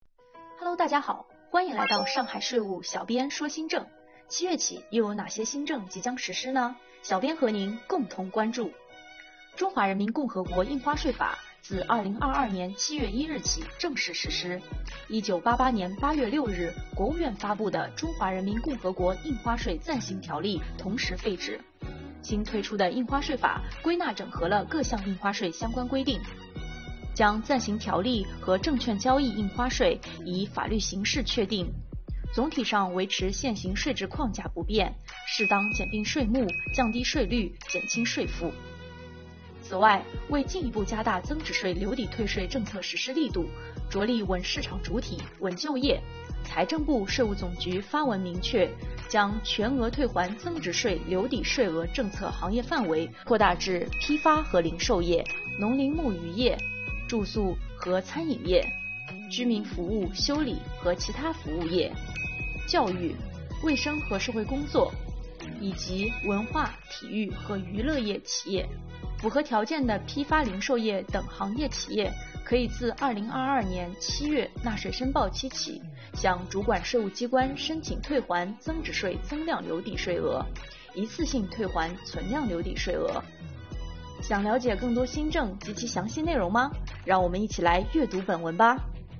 小编说新政